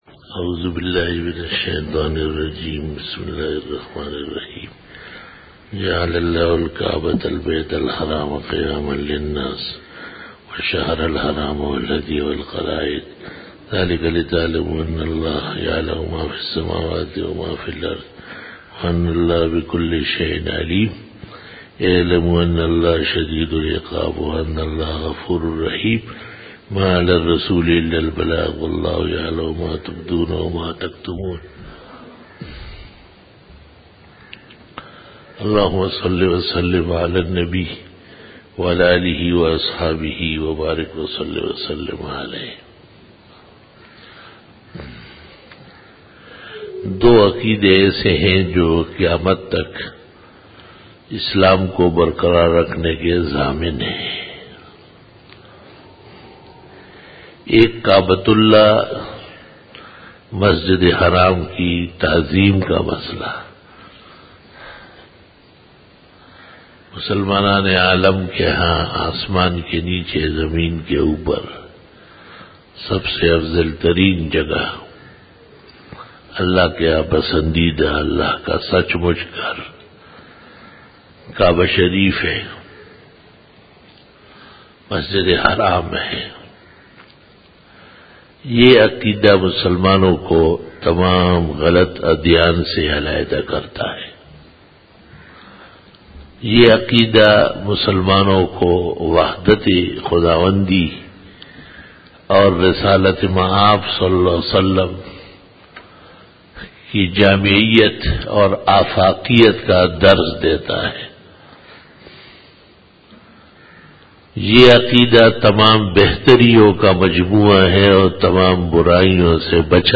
بیان جمعۃ المبارک
Khitab-e-Jummah 2012